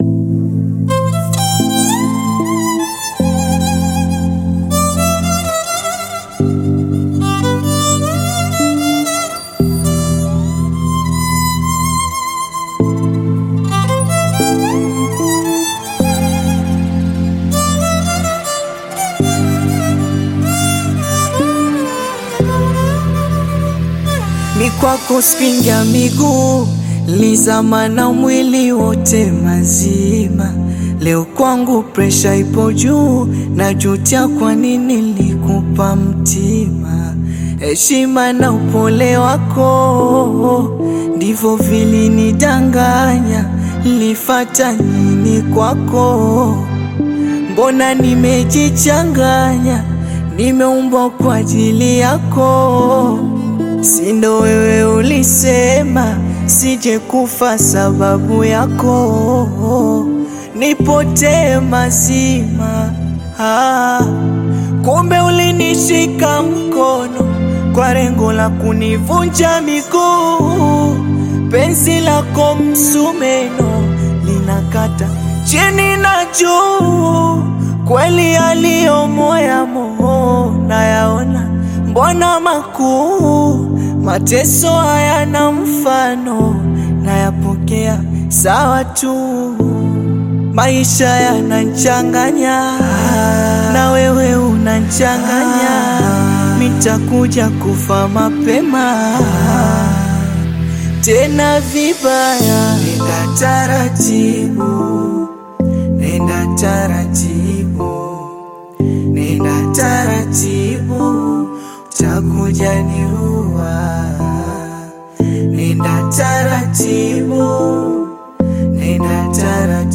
Tanzanian Bongo Flava artist, singer, and songwriter
Bongo Flava You may also like